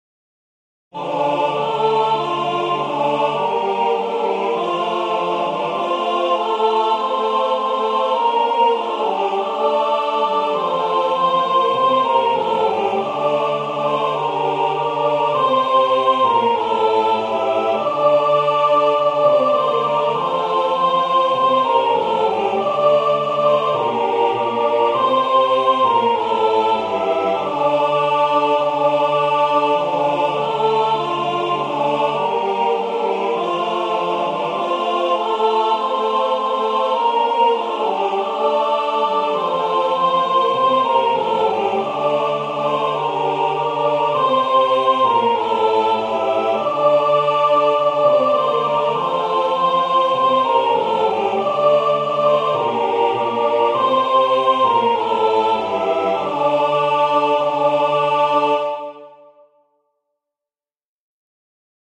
Ноты, партитура голосов, хор
Прослушать НОТЫ (Вокальная партитура):